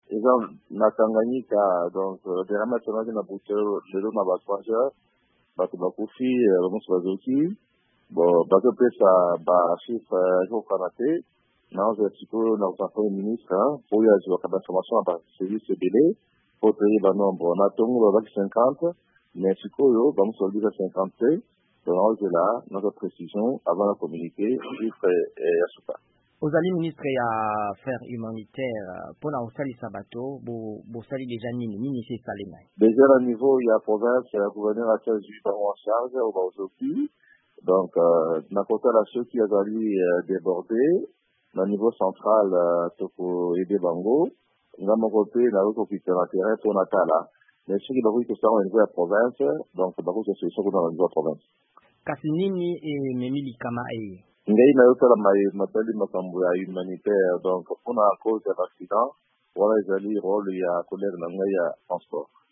Bato bayike bakufi mpe basusu bazoki nsima na bokweyisami ya engunduka moko ya biloko pene na mboka Mayimaridi na Tanganyika. Ministre ya mambi bosalisi bato (Affaires humanitaires), Steve Mbikayi ayebisi VOA Lingala ete bolukiluki bozali kosalama mpo na koyebisa motango ya solo ya bawei.